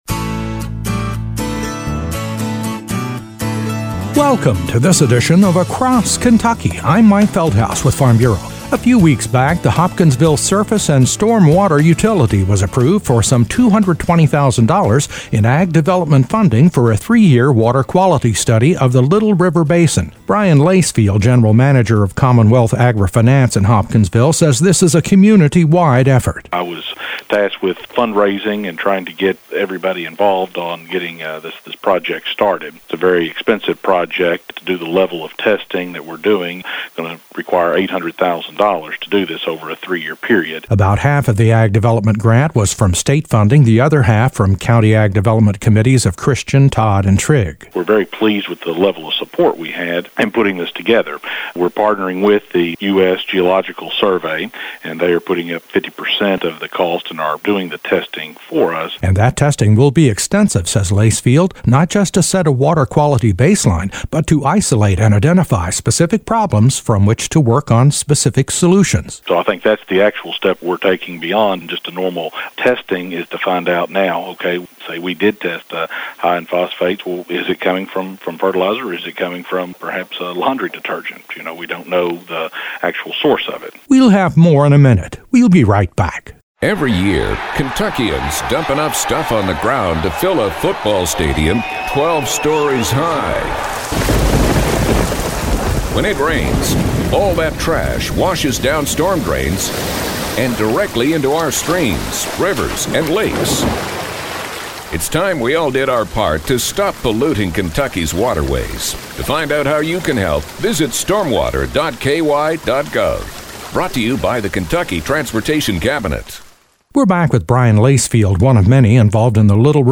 A report on a $220,000 ag development grant awarded to the Hopkinsville Surface & Stormwater Utility to do a water quality study of the Little River Basin covering Christian, Todd and Trigg Counties.